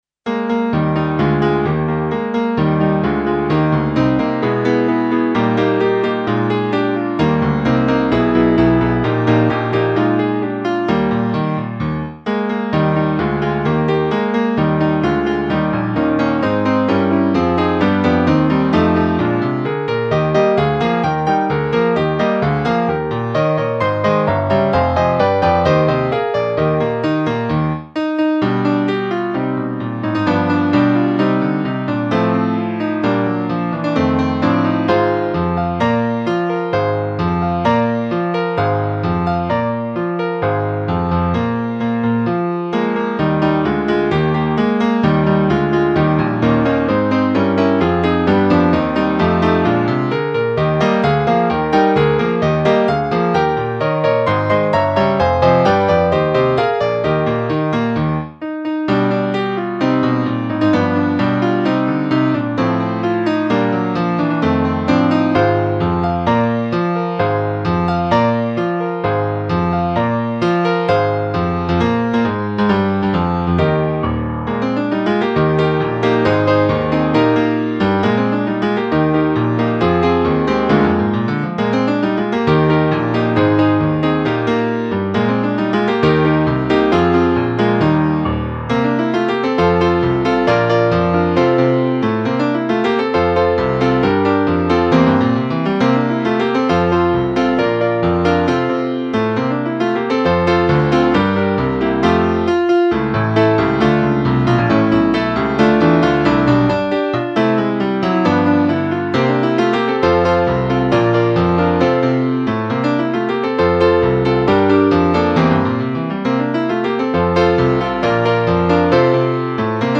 (Base Mp3)
arlecchini base.mp3